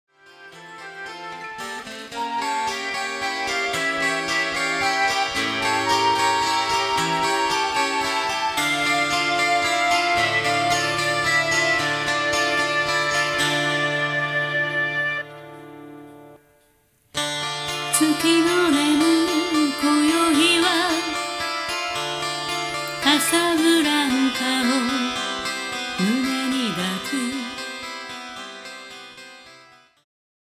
ジャンル Progressive
シンフォニック系
ハード系
2010年スタジオ録音